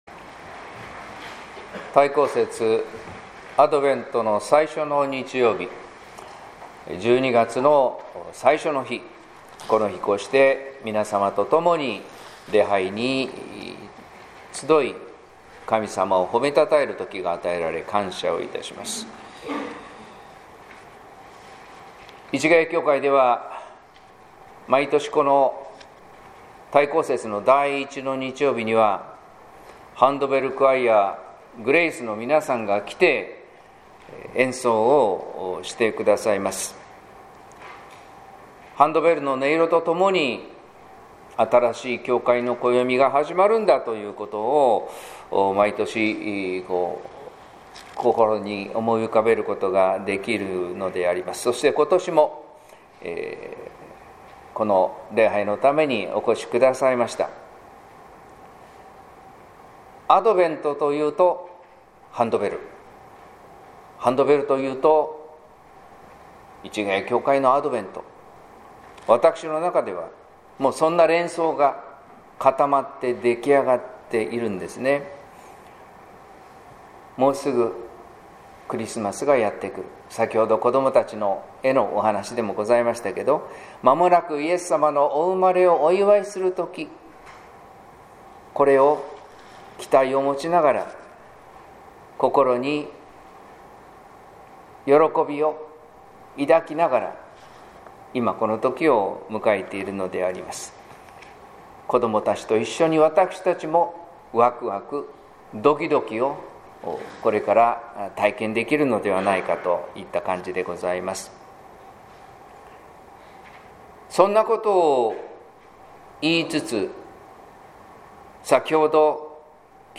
説教「私たちのアドベント」（音声版）